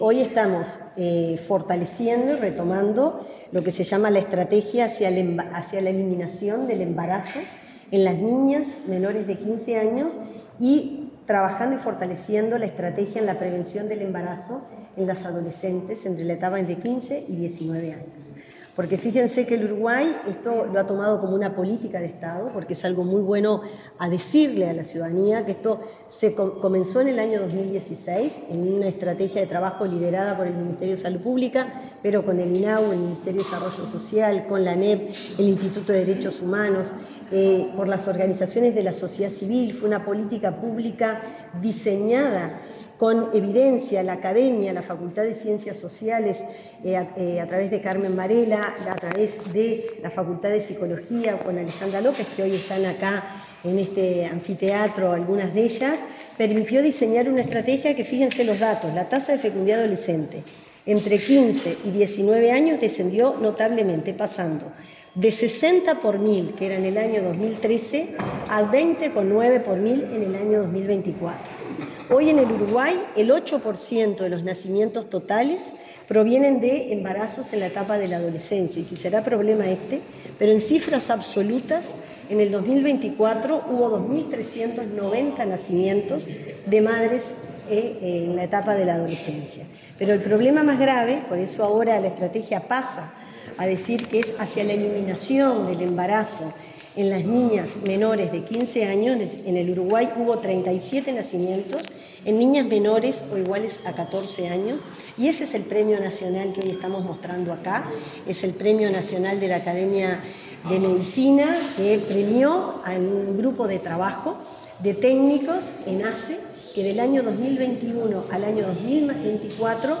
Declaraciones de la ministra de Salud Pública, Cristina Lustemberg